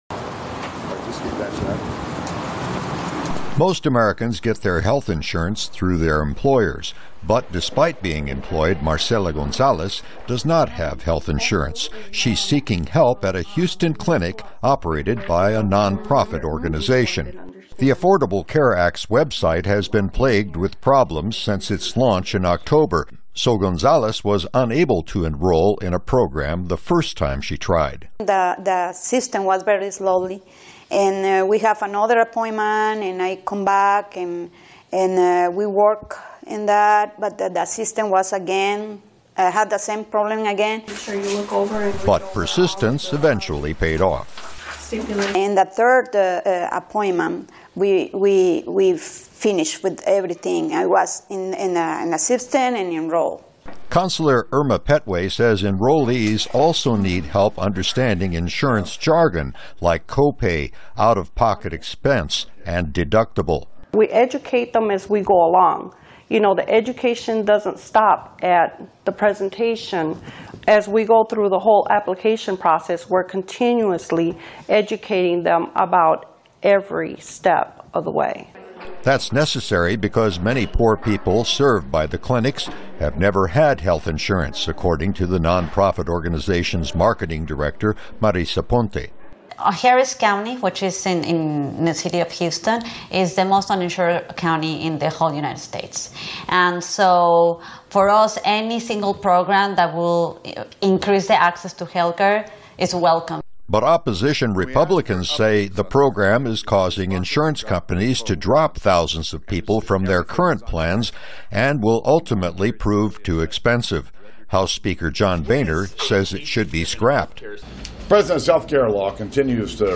VOA常速英语|医保顾问协助平价医保投保人(VOA视频)